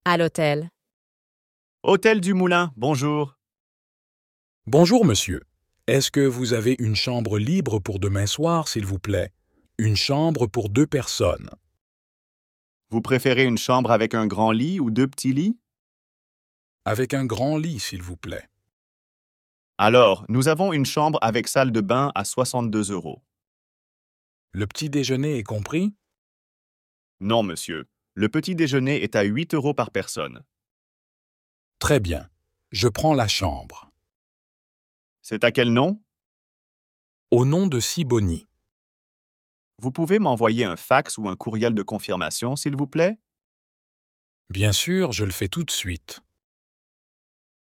Dialogue FLE - à l'hôtel